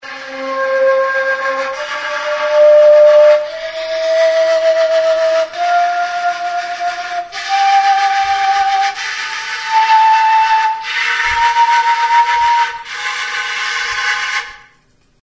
The Xiao
The long bamboo flute Xiao is the closest friend of the Qin zither.
It's delicate soft tone is easily recognized and demands high playing skills.
The model shown above has six playing and four open holes, is tuned pentatonic on the F key and has a range of two octaves.
xiao.mp3